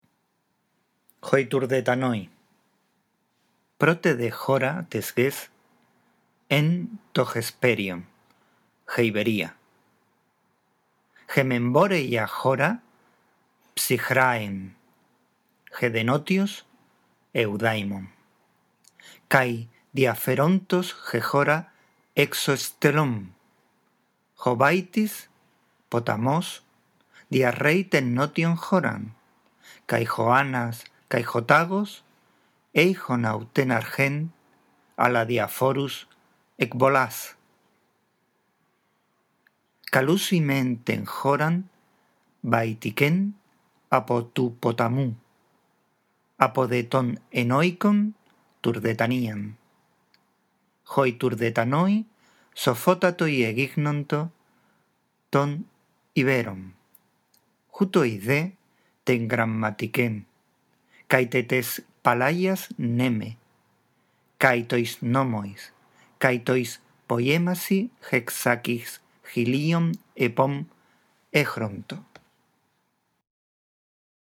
La audición de este archivo te ayudará en la práctica de la lectura en voz alta